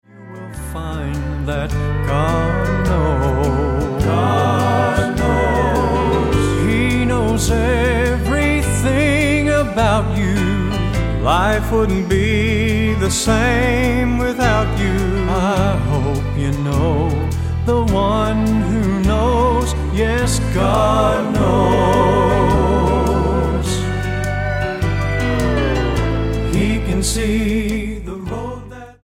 STYLE: Southern Gospel
tender harmonies